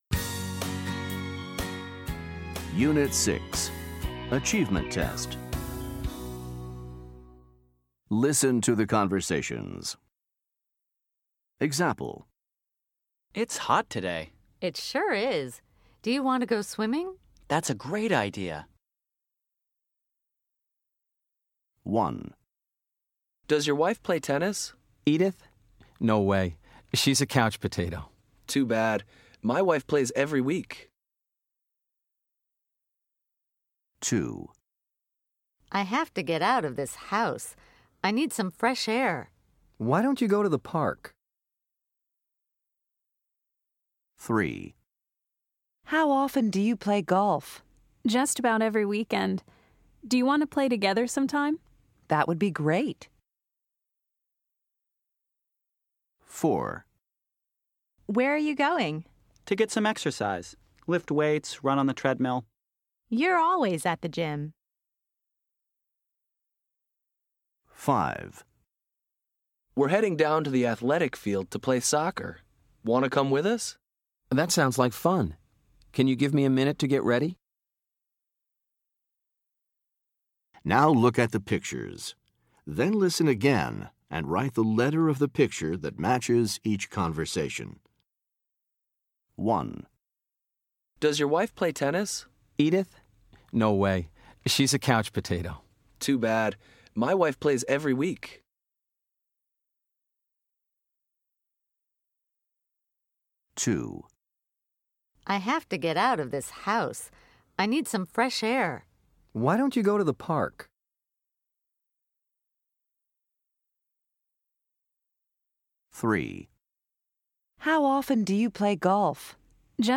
Listen to the conversations and look at the pictures.